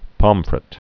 (pŏmfrət)